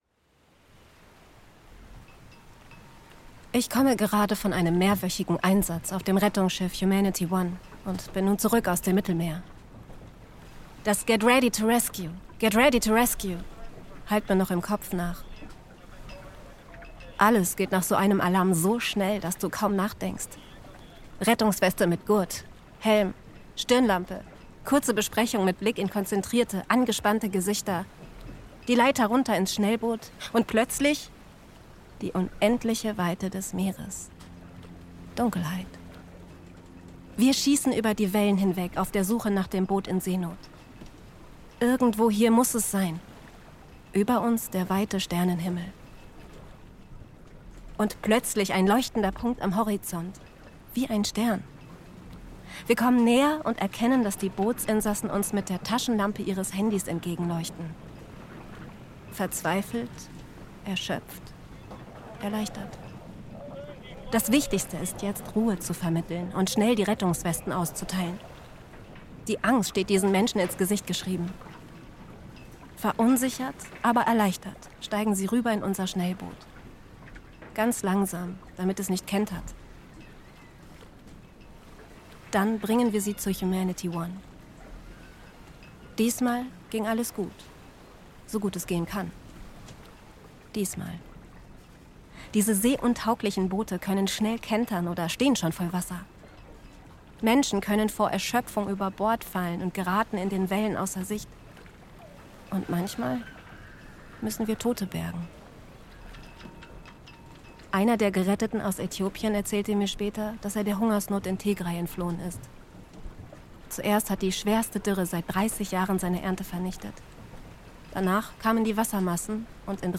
Seenotretter*innen erzählen: